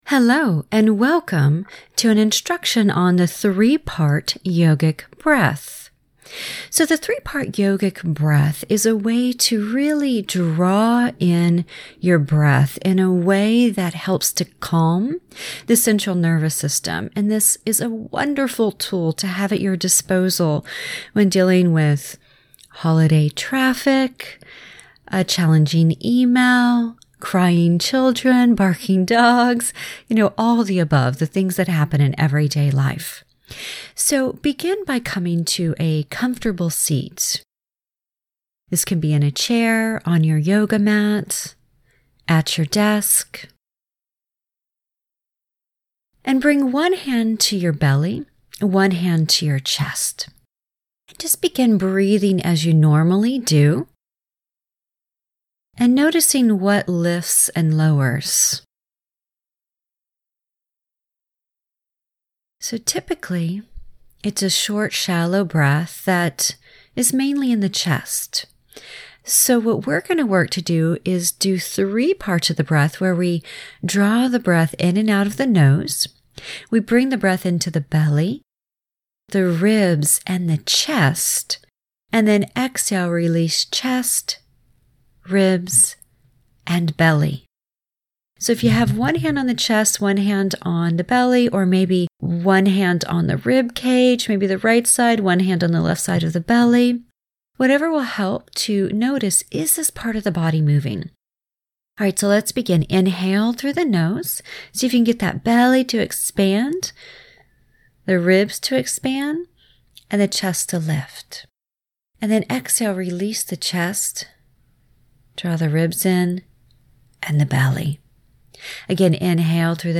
3-part breath.mp3